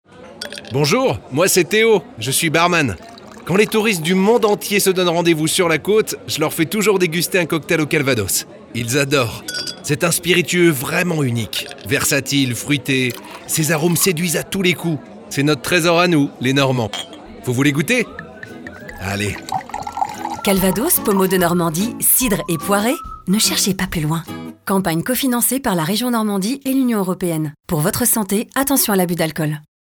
Une campagne radio pour soutenir la filière
IDAC SPOT COCKTAIL.mp3